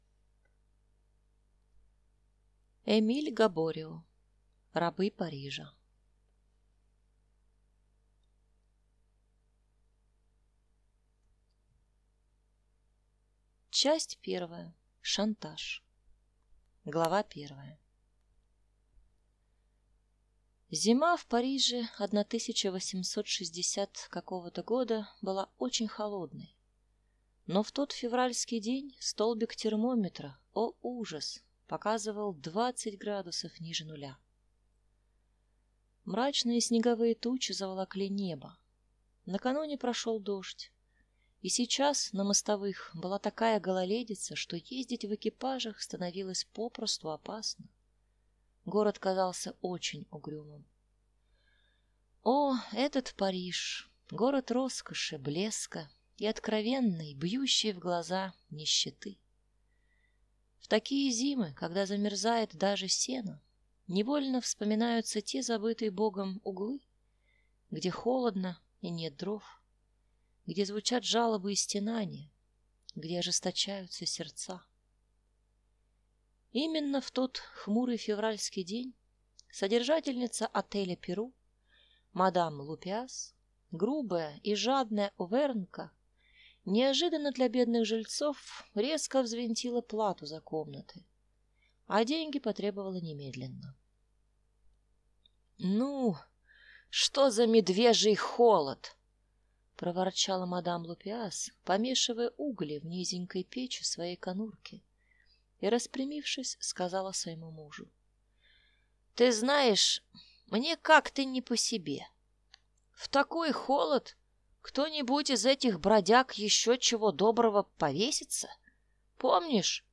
Аудиокнига Рабы Парижа | Библиотека аудиокниг